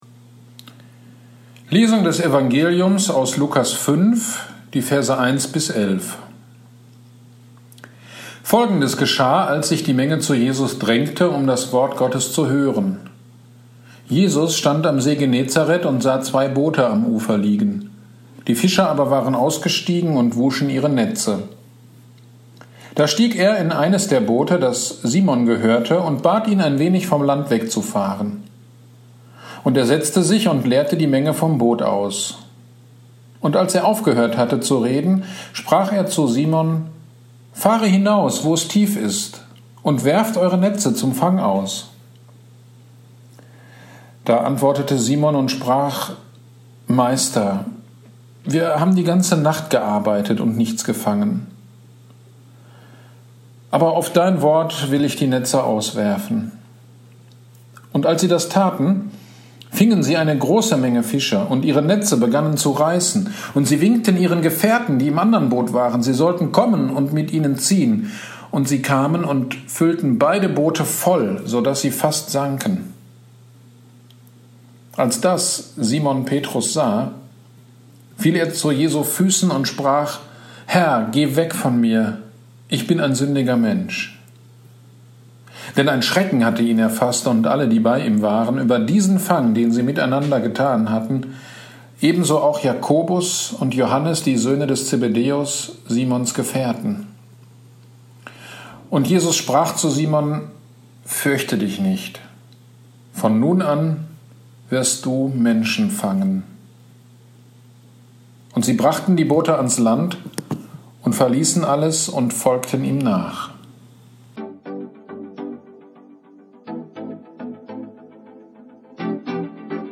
Predigt zu Lukas 5,1-11 am 12.07.2020 - Kirchgemeinde Pölzig